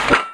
fire_dry.wav